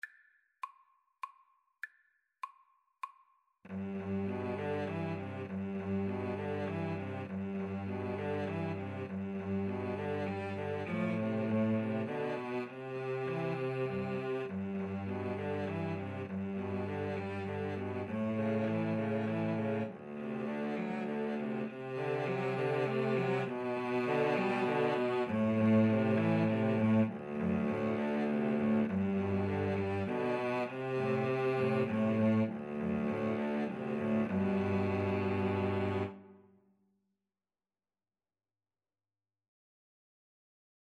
17th-century English folk song.
G major (Sounding Pitch) (View more G major Music for Cello Trio )
Moderato
Cello Trio  (View more Easy Cello Trio Music)